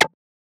edm-perc-21.wav